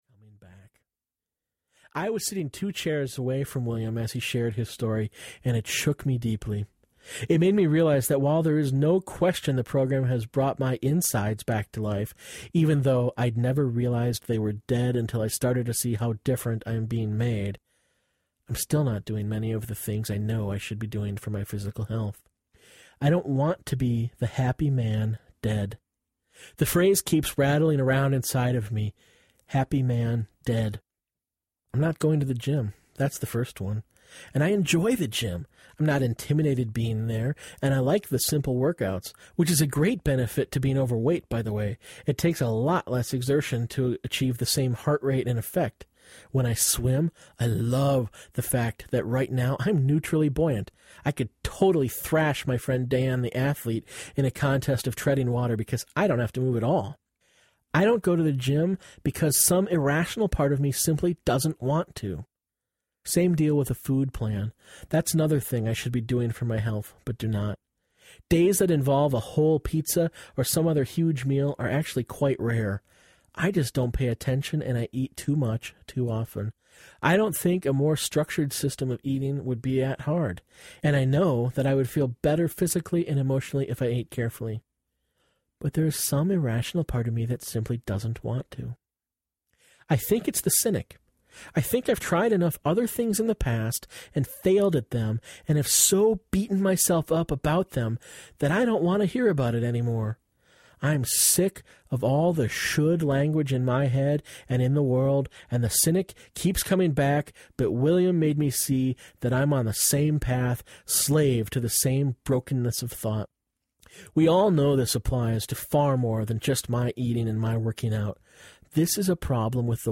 Learning My Name Audiobook
Narrator
4.2 Hrs. – Unabridged